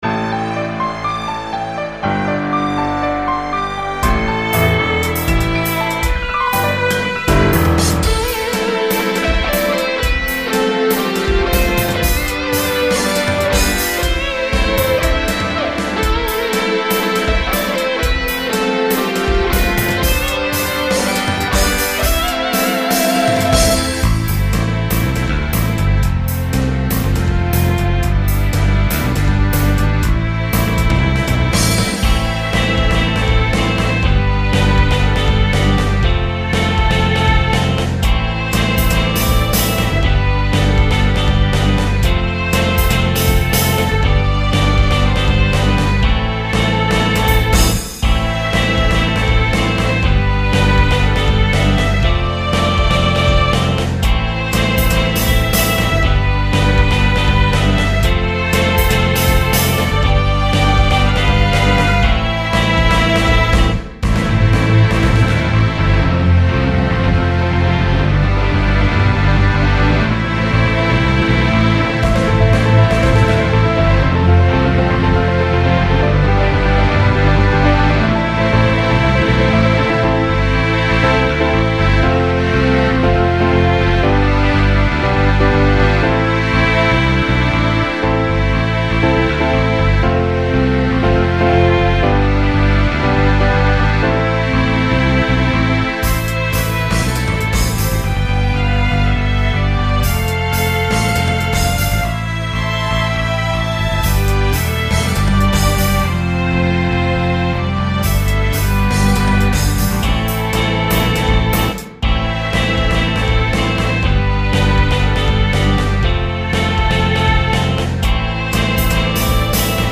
ほとんどソフトウェア音源だけで作成してます。
ギターは自分で弾いてるけど、実力が足りなすぎる。